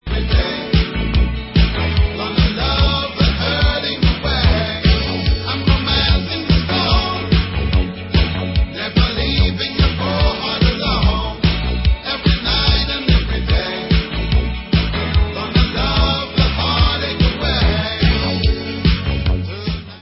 sledovat novinky v oddělení World/Reggae